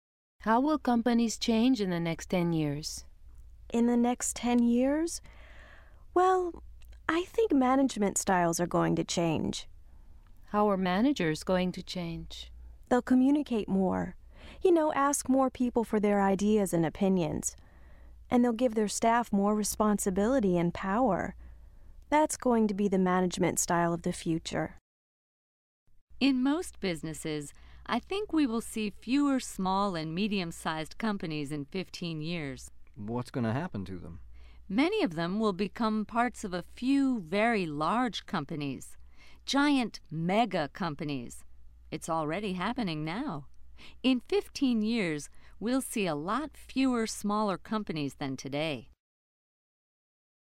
Listen to people making predictions about the future.